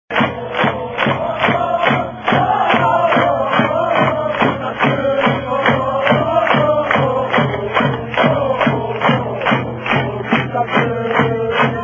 南長野運動公園総合球技場 2281人
３点差に開いて終了５分を切って、ゆっくりとした草津節の大合唱が始まったいね。
終了後の歓喜の草津節の大合唱だぃね。）